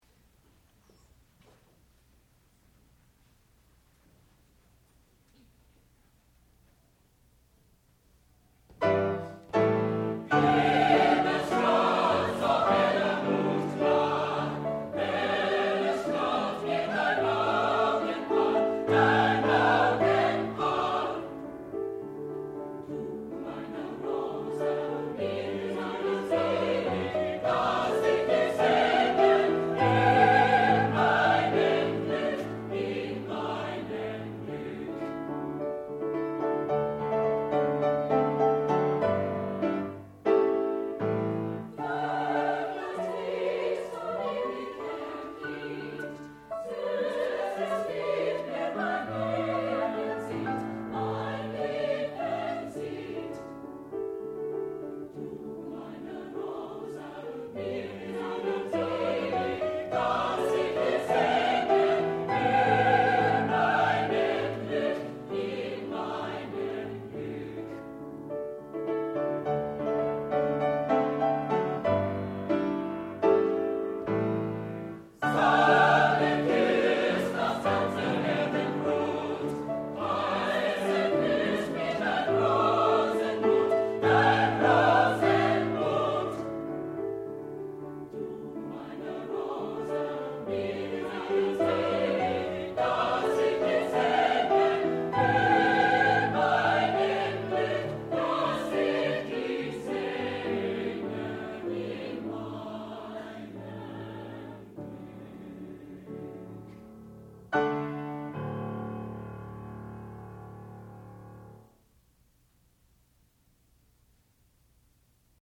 classical music